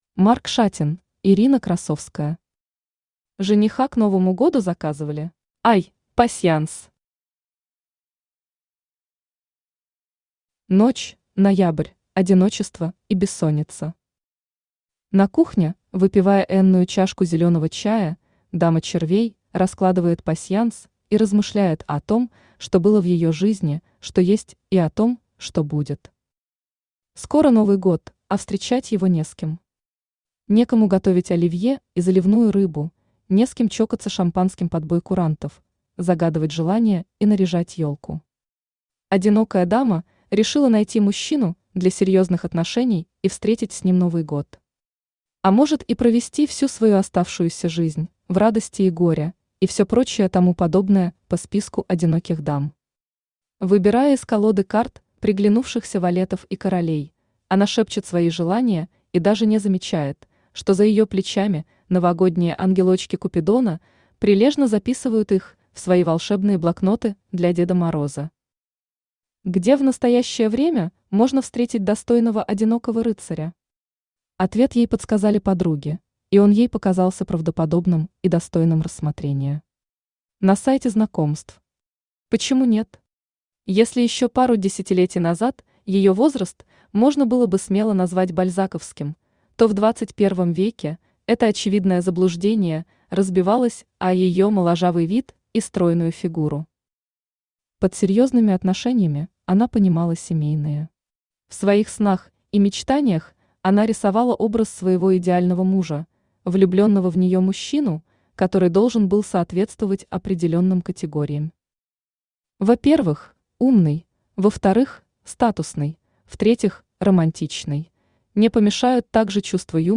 Aудиокнига Жениха к Новому году заказывали? Автор Ирина Красовская Читает аудиокнигу Авточтец ЛитРес.